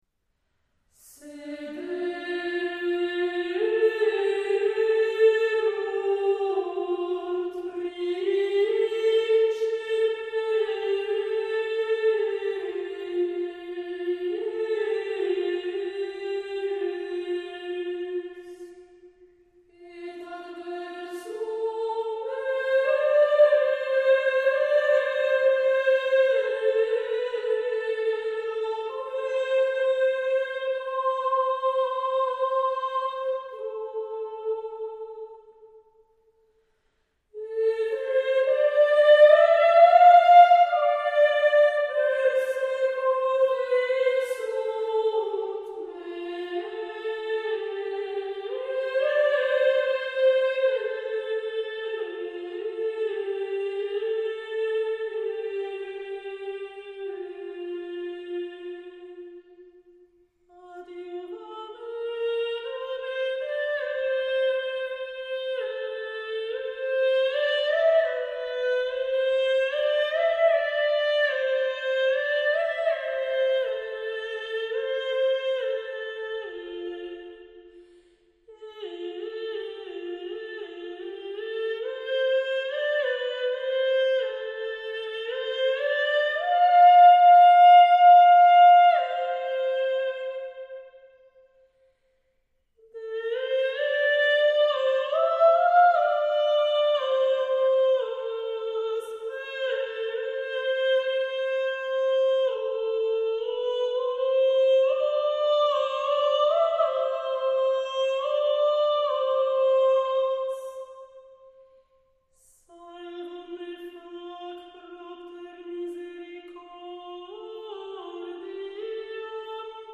Graduale
È fra i canti più ornati e melismatici della messa (da qui il nome all'intero libro che raccoglie tutti i canti della messa). Usa un ambitus piuttosto ampio e in genere il versetto è in posizione più acuta del responsum.
I vocalismi indugiano in ripetizioni melodiche e centonizzazioni, ovvero giustapposizioni di frammenti melodici già presenti in altri canti.